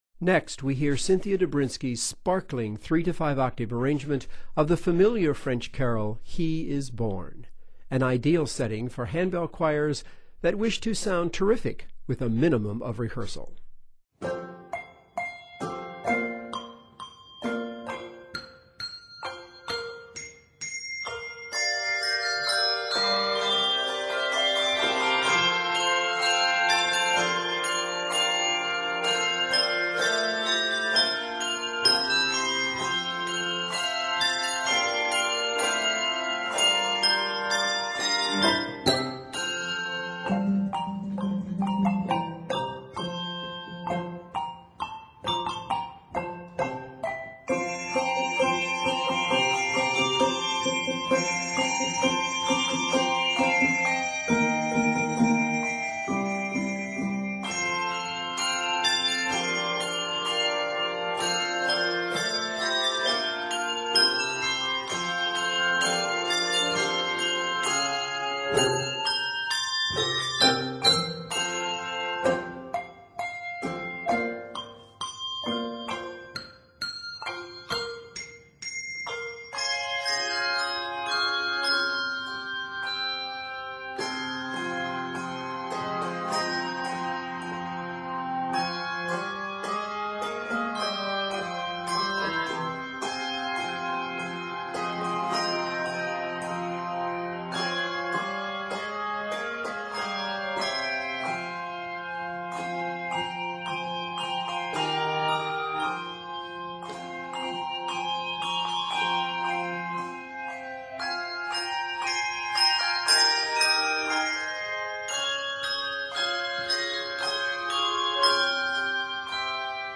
familiar French carol